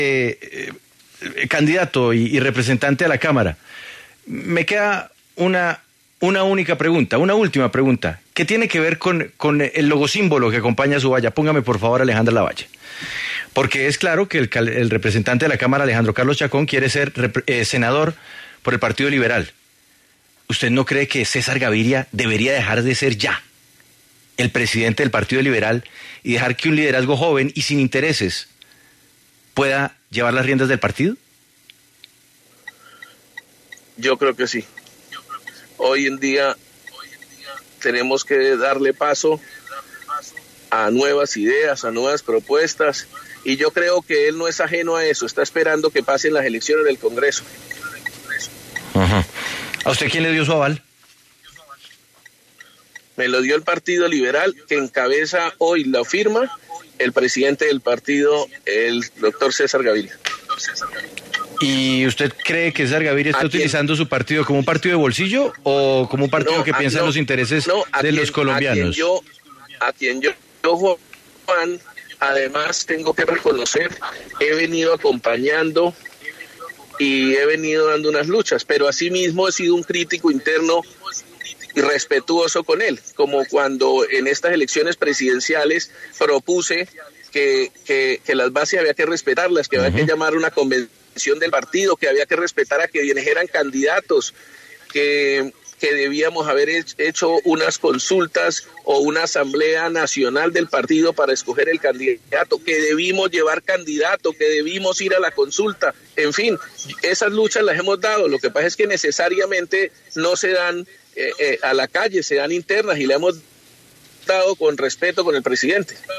Declaraciones del representante a la Cámara luego de que en Sigue La W se le interrogó sobre si César Gaviria debería dejar de ser el presidente del partido Liberal.
Declaraciones Alejandro Chacón sobre Cesar Gaviria